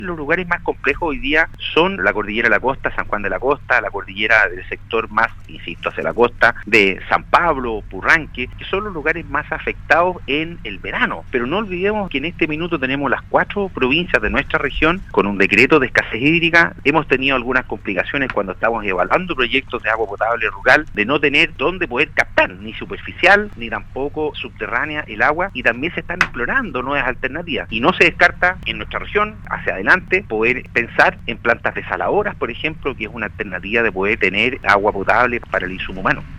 En entrevista con Radio Sago, el Seremi de Obras Públicas James Fry, explicó que con esta modificación se priorizará el consumo humano y después el uso de agua para producción agrícola.